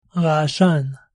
"rah-ah-SHAN"
raashan.mp3